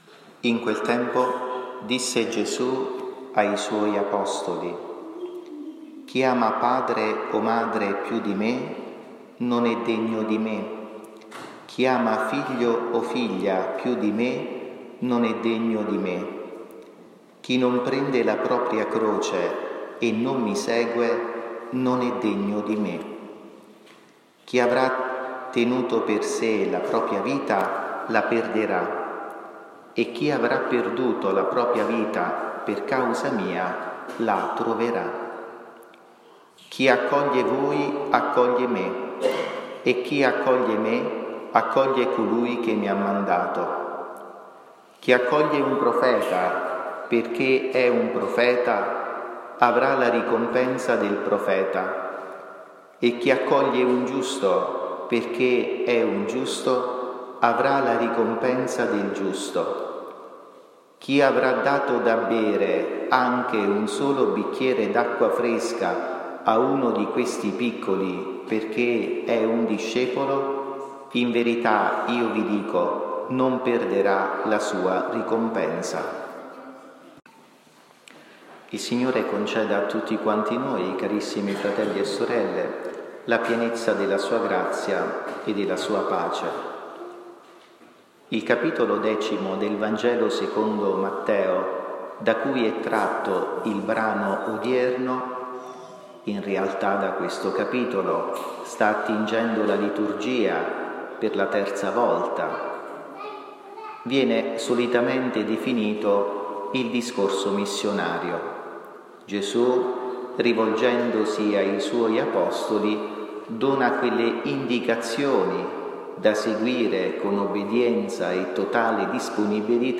omelia-2-luglio-2023.mp3